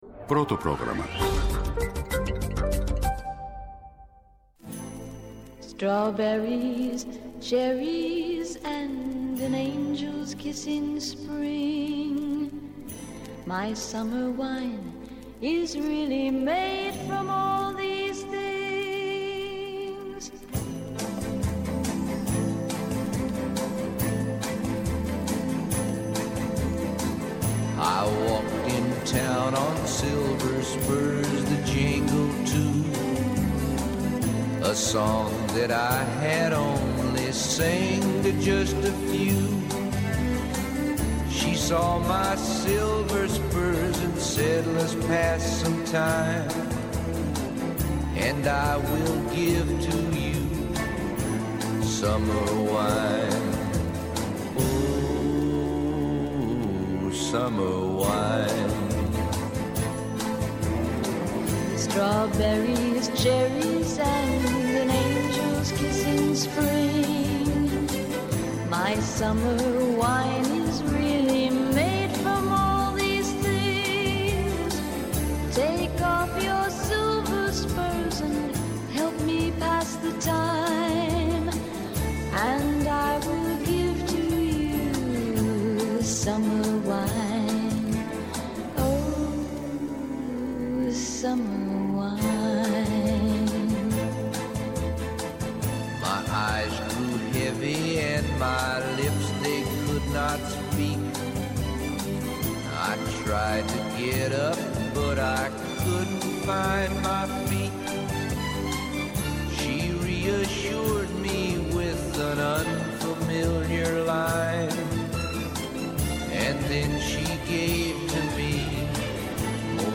Καλεσμένη απόψε η ηθοποιός και σκηνοθέτης